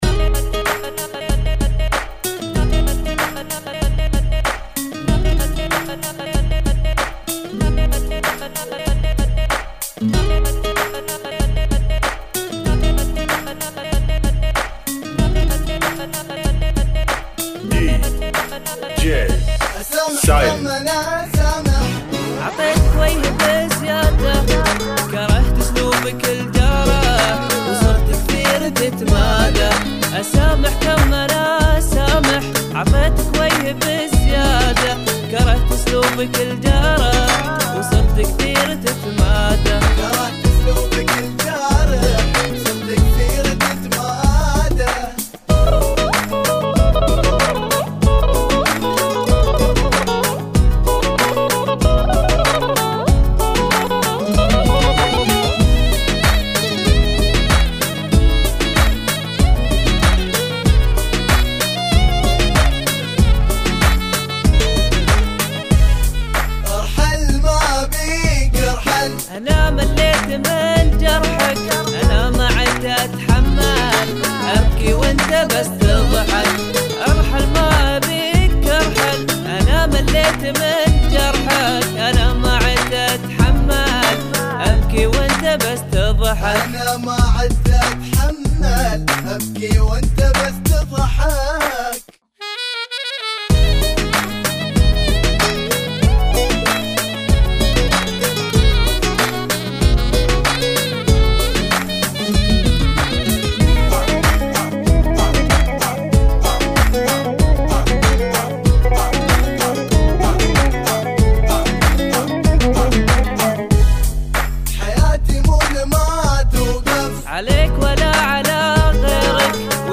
[ 95 BPM ]